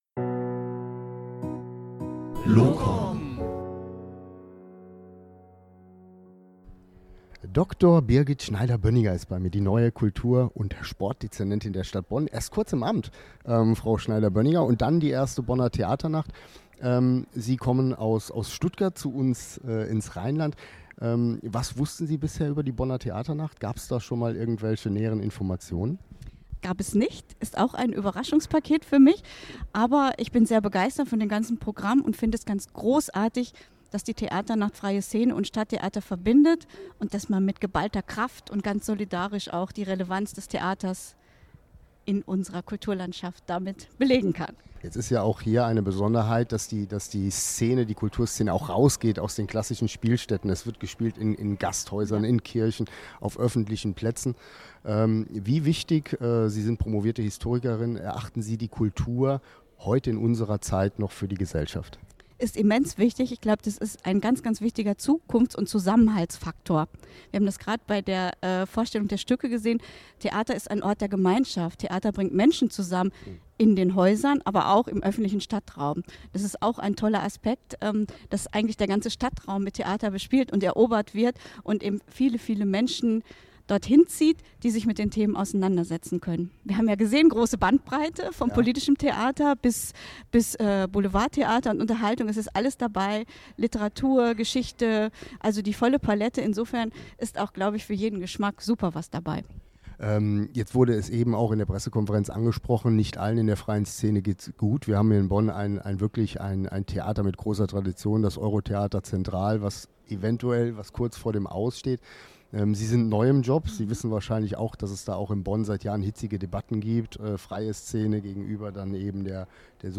LoComNET Interviews zur Bonner Theaternacht 2019